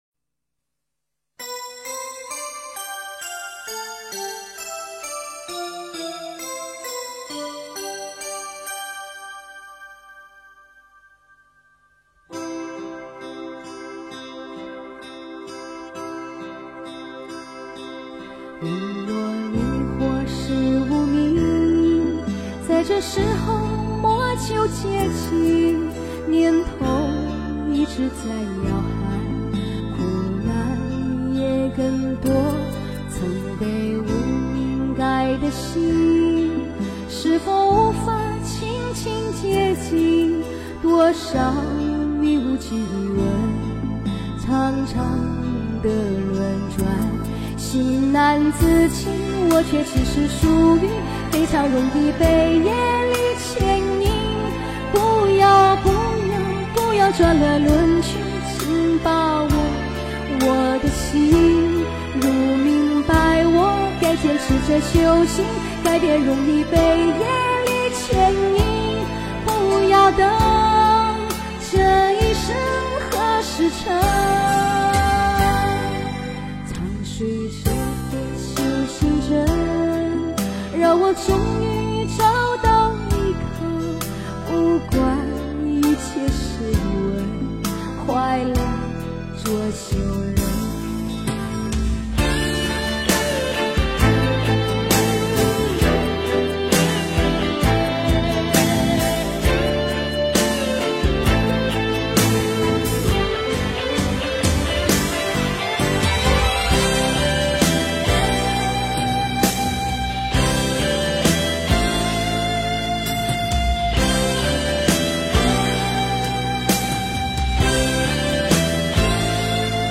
容易被业力牵引 诵经 容易被业力牵引--佛教音乐 点我： 标签: 佛音 诵经 佛教音乐 返回列表 上一篇： 清净无为功 下一篇： 不变 相关文章 绿度母心咒--齐豫 绿度母心咒--齐豫...